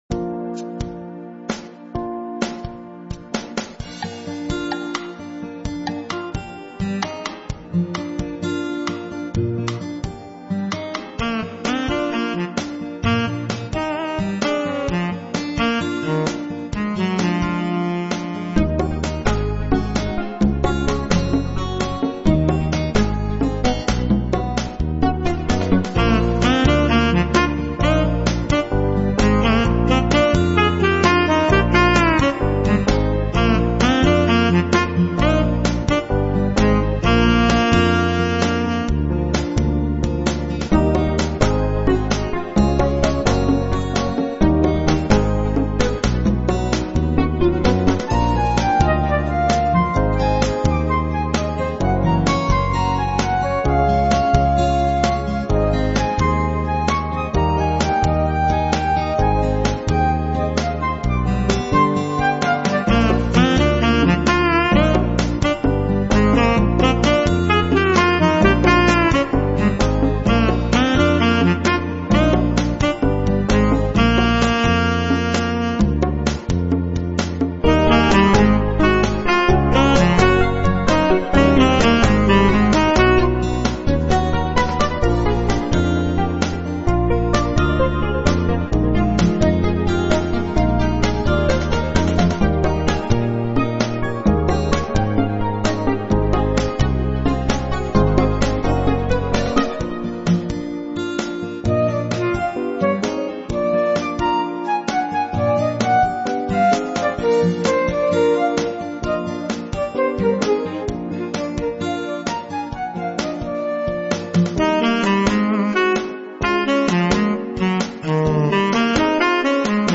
Playful Instrumental Pop with Synth Saxophone and Flute lead